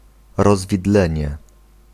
Ääntäminen
Ääntäminen Tuntematon aksentti: IPA: [rɔzviˈdlɛɲɛ] Haettu sana löytyi näillä lähdekielillä: puola Käännös Konteksti Ääninäyte Substantiivit 1. fork US UK 2. bifurcation maantiede Suku: n .